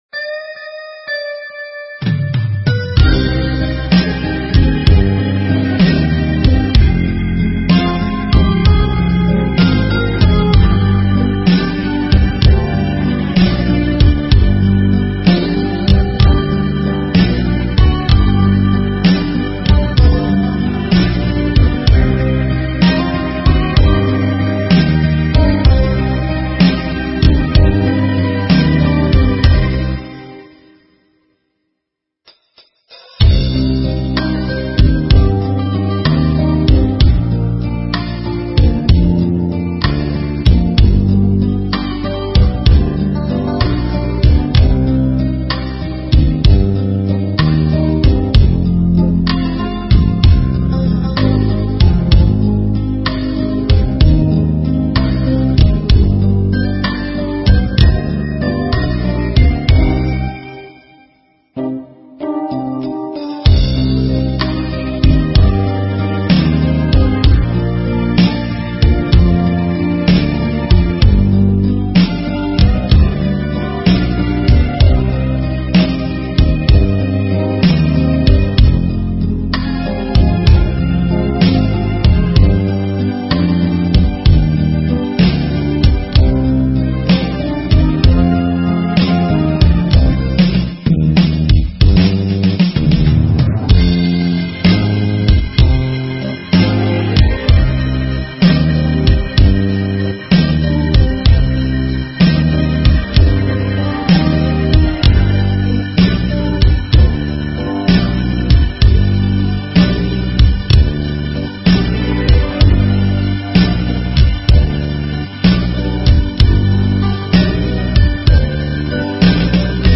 【★반주.명상.가사★】/♬~반주.연주.경음악